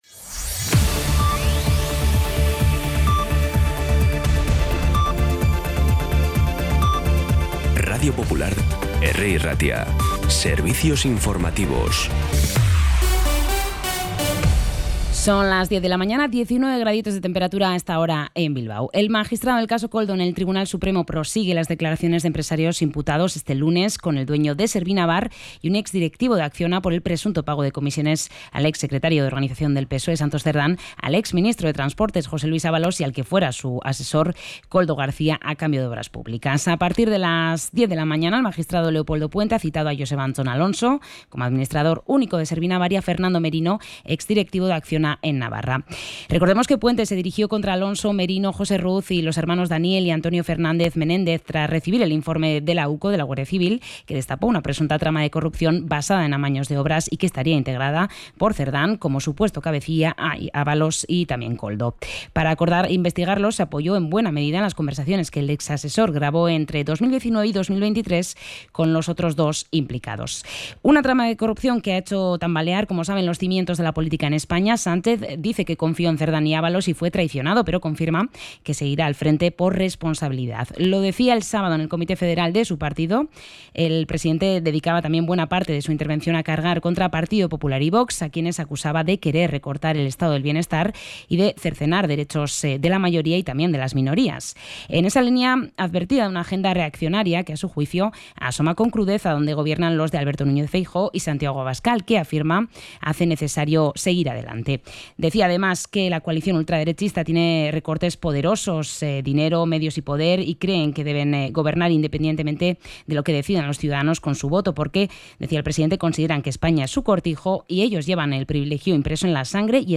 Información y actualidad desde las 10 h de la mañana